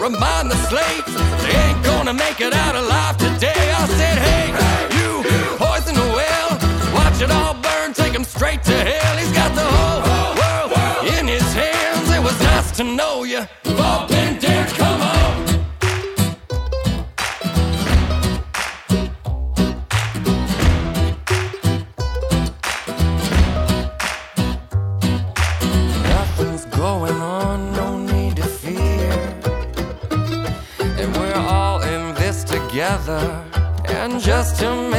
# Americana